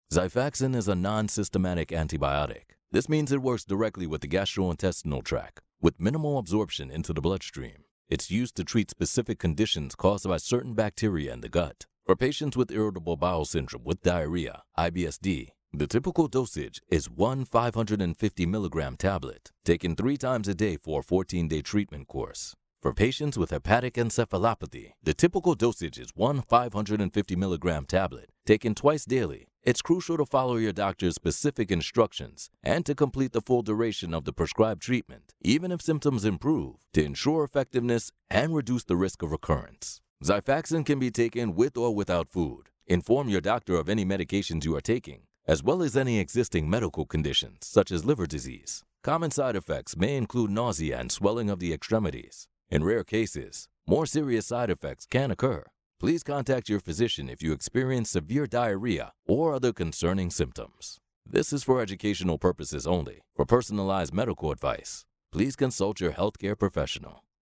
Profound. Resonant. Real | Voiceovers
Elearning Voiceovers
To ensure a superior auditory experience for your learners, my studio is equipped with an isolated sound booth, meticulously designed to eliminate external interference and create a pristine recording environment.
The result is a clean, crisp, and professional voiceover that enhances the learning experience rather than detracting from it.